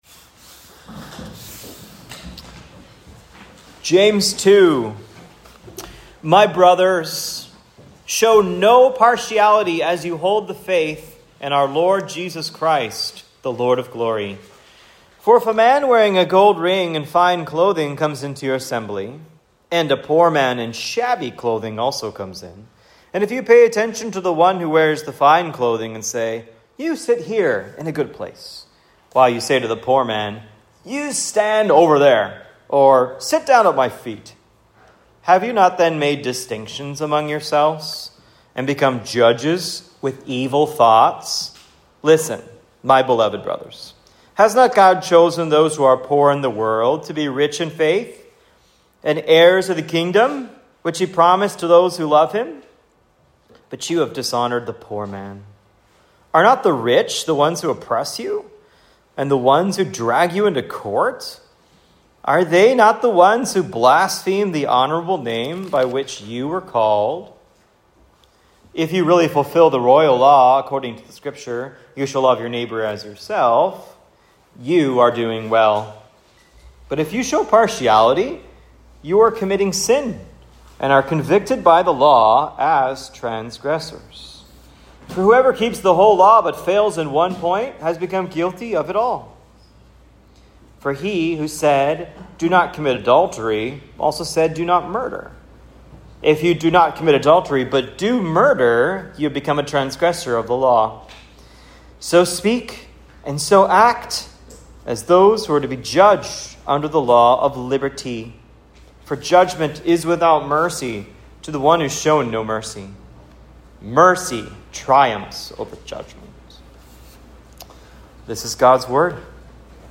Here's a sermon based on James 2:1-13. What is the answer to prejudice and partiality?
(Preached at Cross of Christ Fellowship in Naperville, Illinois on 7/6/25)